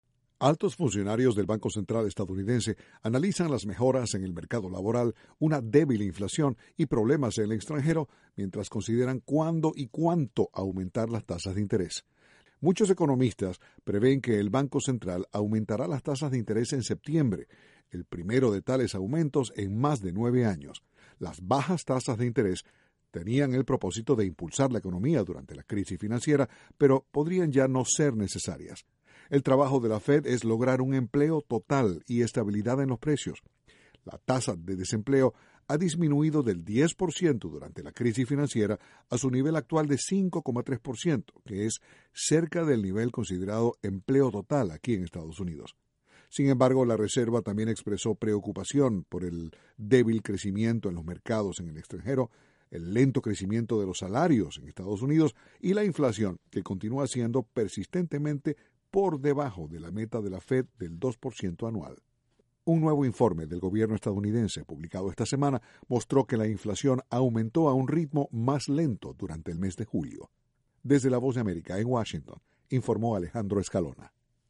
Noticias sobre economía en Estados Unidos.